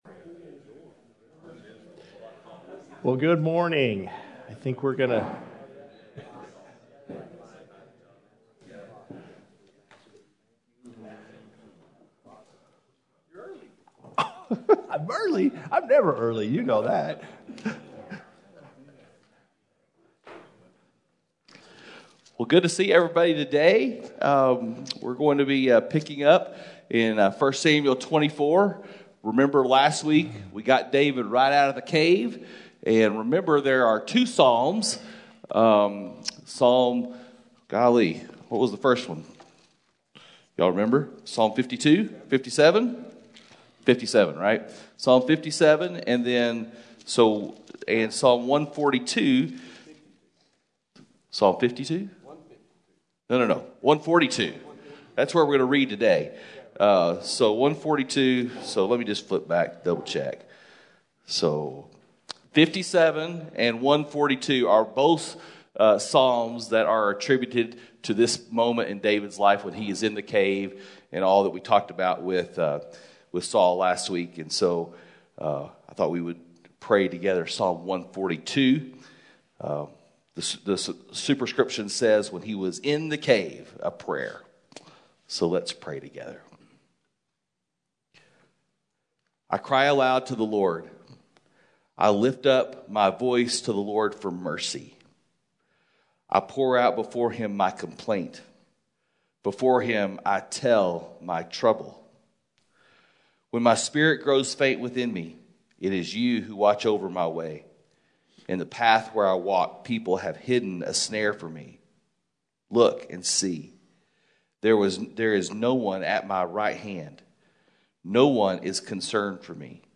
Men’s Breakfast Bible Study 5/18/21